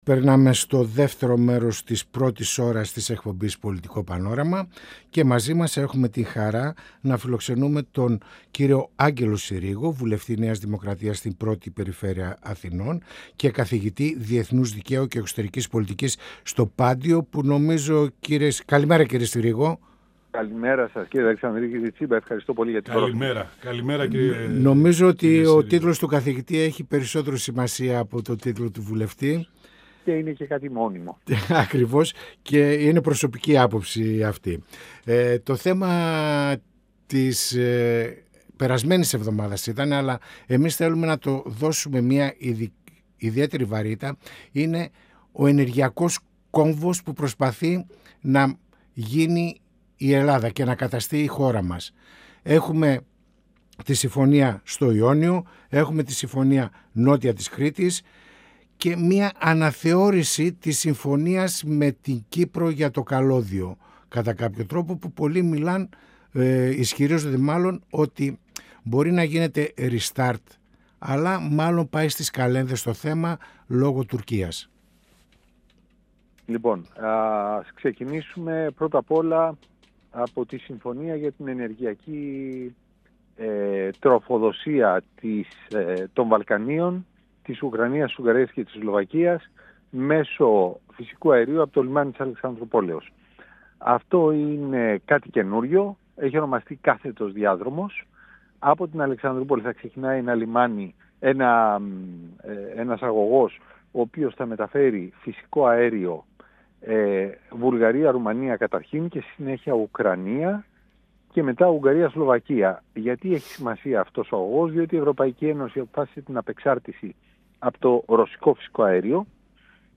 Στις πολυσήμαντες συμφωνίες για τα ενεργειακά, στα ελληνοτουρκικά και στις προτεραιότητες της κυβερνητικής πολιτικής αναφέρθηκε ο Βουλευτής της ΝΔ Άγγελος Συρίγος, μιλώντας στην εκπομπή «Πανόραμα Επικαιρότητας» του 102FM της ΕΡΤ3.